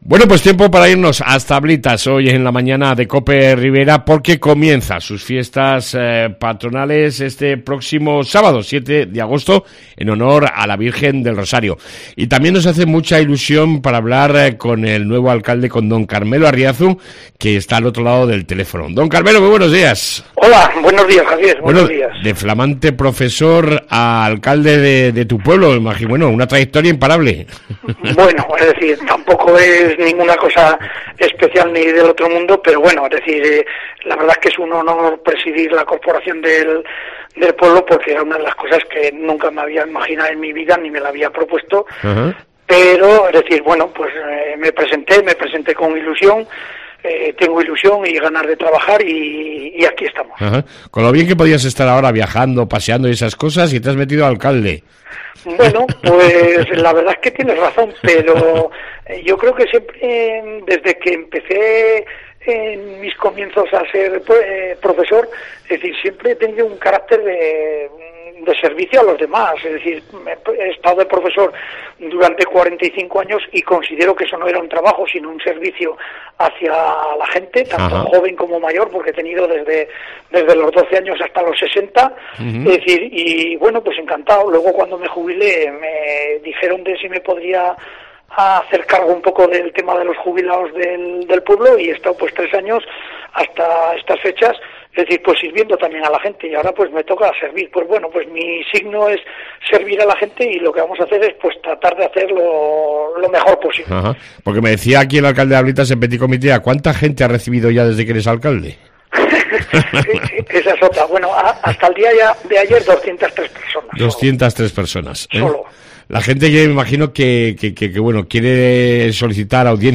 AUDIO: Ablitas y sus fiestas formaron parte de nuestra entrevista.